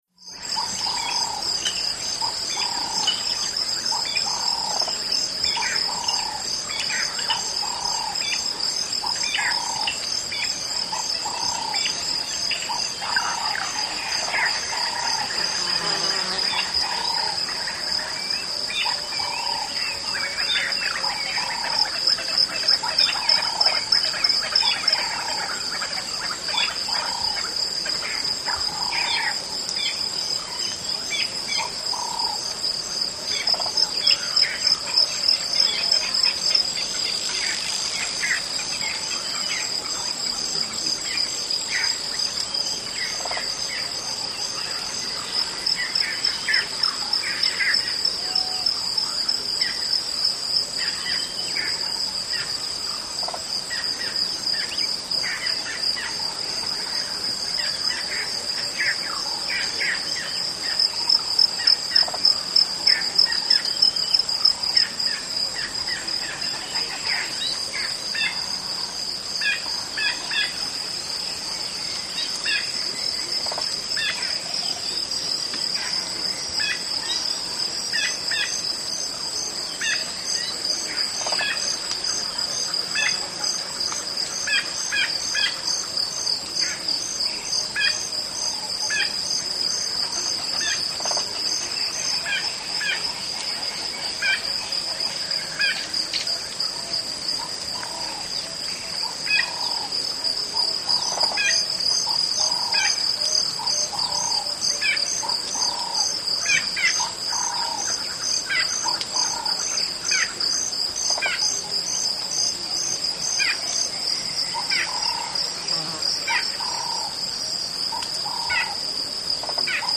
ANIMALS-INSECTS Background AFRICA: Crickets with distant thunder, start of rain, Ruwa, Zimbabwe. Zimbabwe atmosphere at evening.